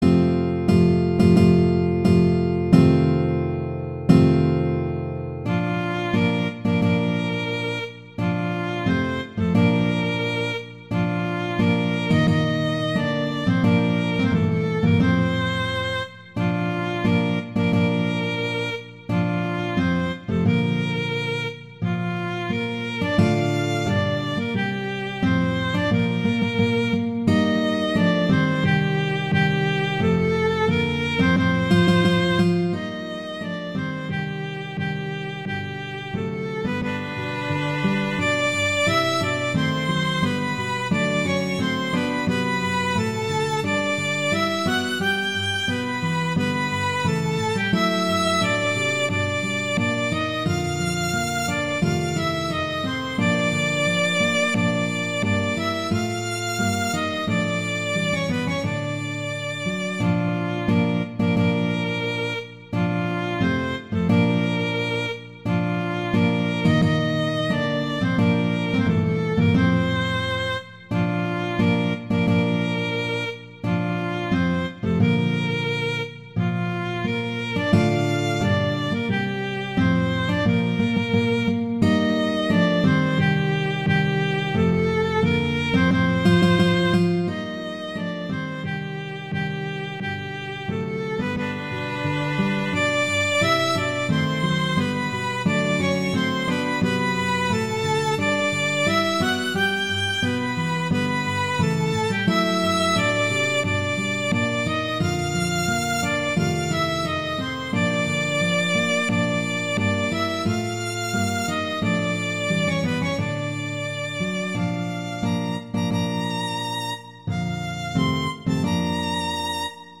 Instrumentation: violin & guitar
arrangements for violin and guitar
wedding, traditional, classical, festival, love, french